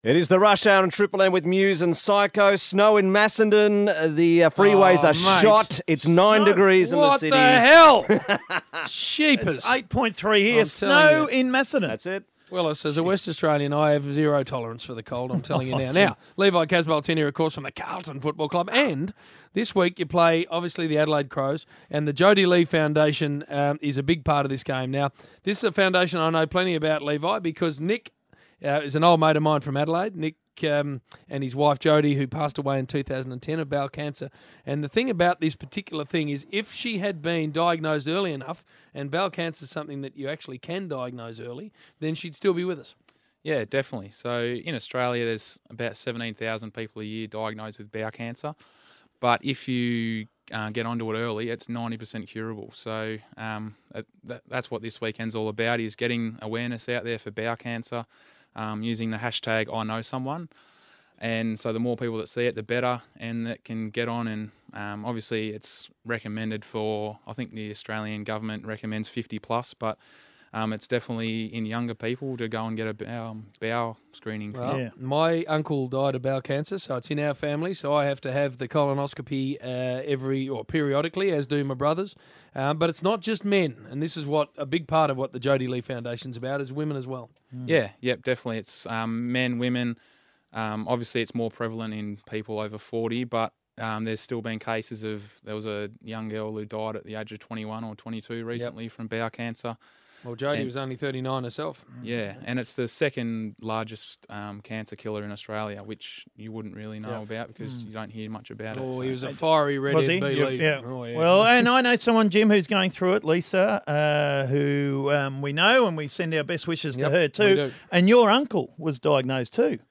Carlton forward Levi Casboult joins the Triple M Rush Hour boys in the studio.